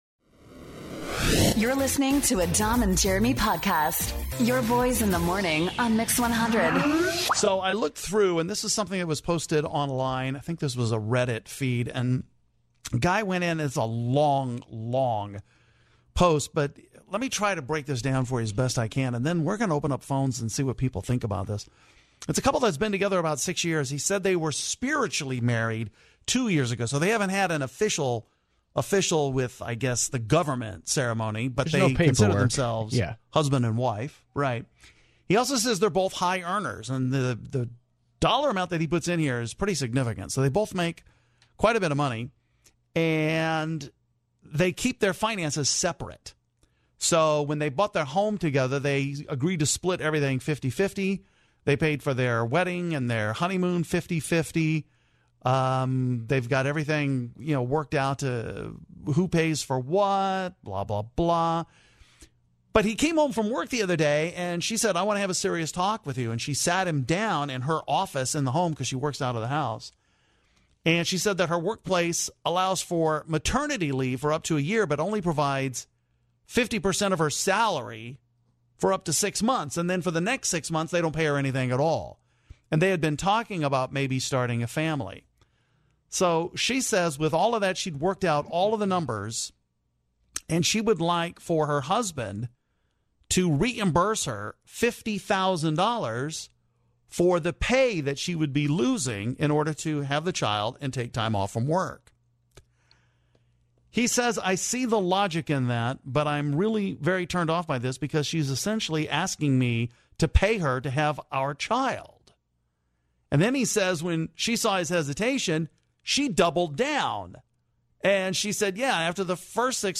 When a woman asked her husband to pay her $50,000 to have their baby, things went viral. Your Boyz In The Morning talked about it, and the calls were spirited.